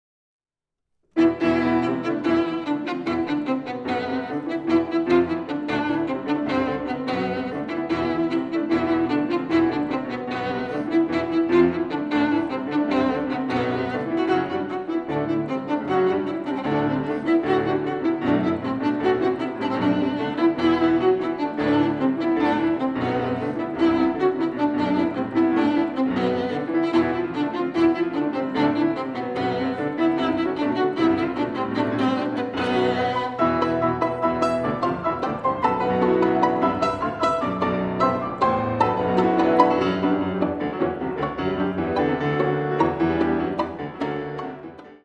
miniatures for violin and piano
piano